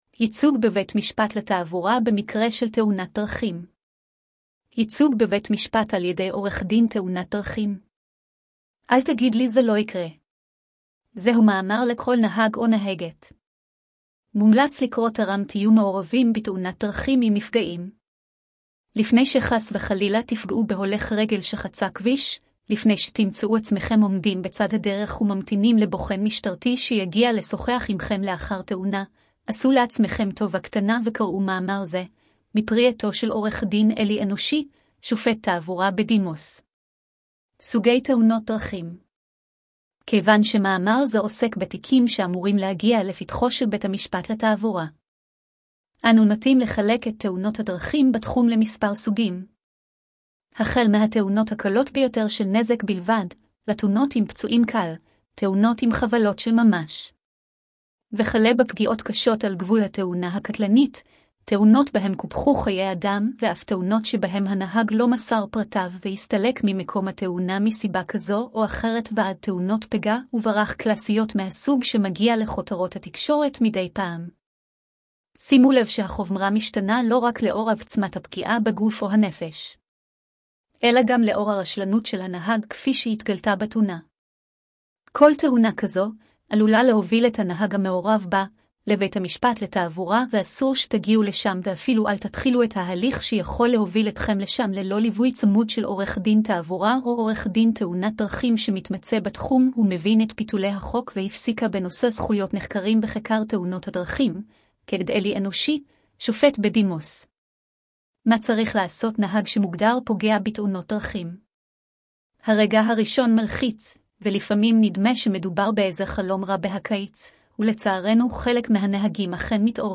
השמעת המאמר לכבדי ראייה: